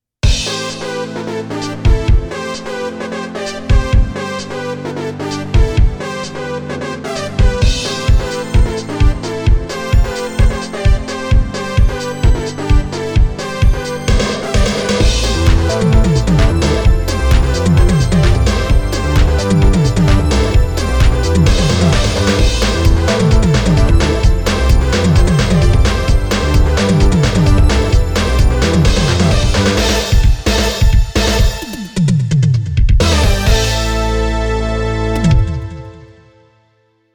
肝心の音については、80年代後半から90年代初頭に流行った、懐かしいシンセの音を収録したものであり、最初に言ったとおり好みは分かれます。
試しに、小一時間ほどでデモ曲を作ってみました。
音源はすべてDigital Sensationsのもの、コンプなどのエフェクトは一部他のプラグインを利用しています。